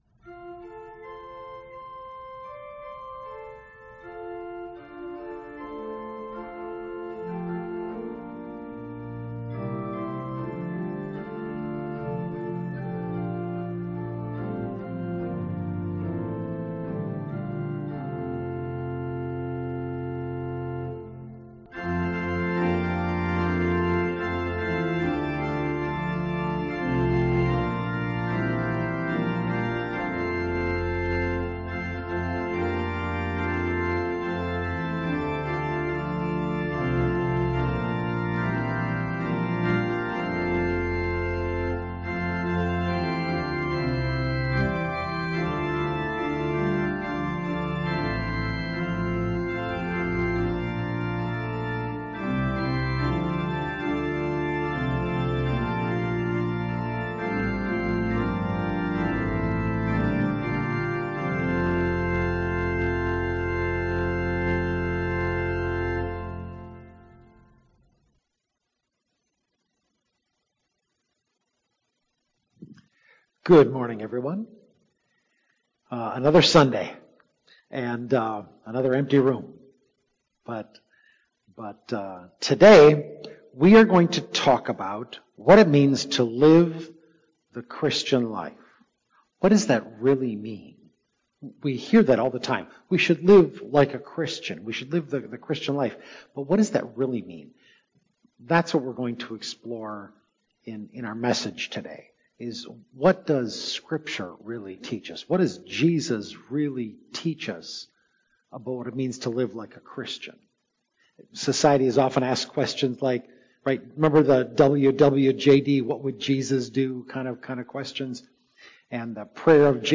Sermon Audio
05-10-St.-Johns-Radio-Broadcast-Living-Like-a-Christian-CD.mp3